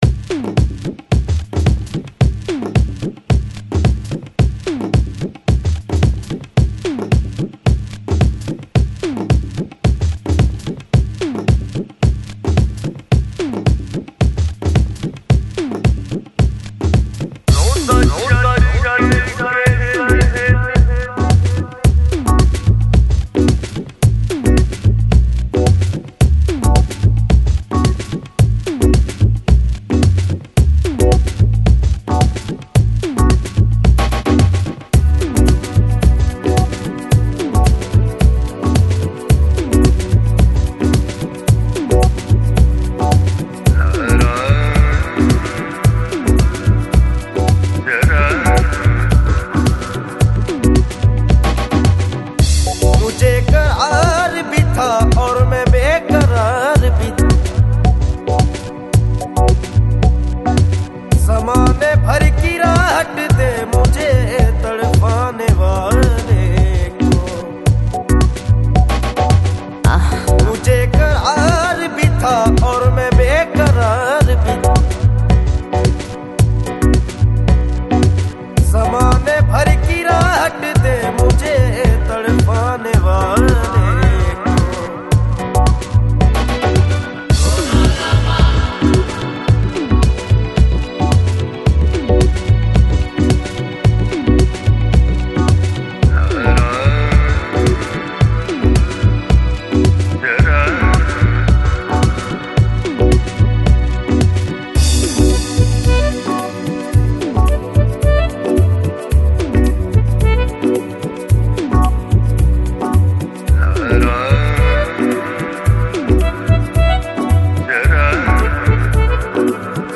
Жанр: Lounge, Chill Out, Downtempo, Electronic